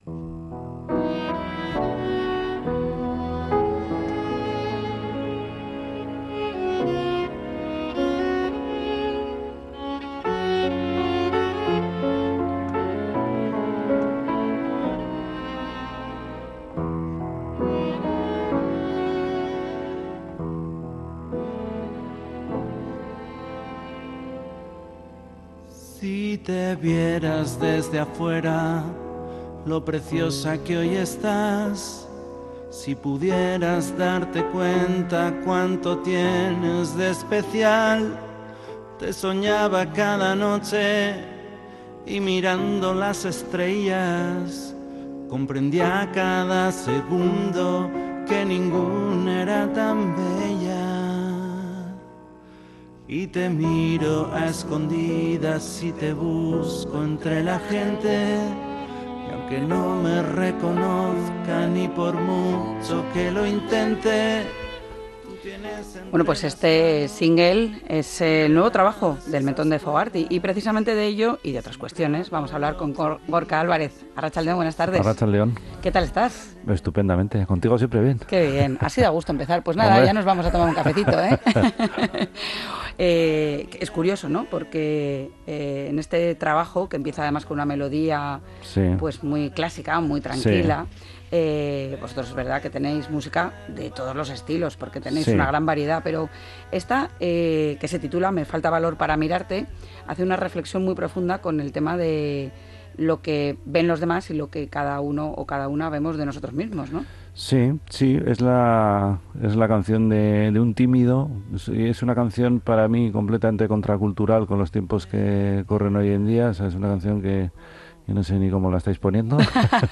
¡Prepárate para la dosis perfecta de diversión y entretenimiento en nuestro programa de radio!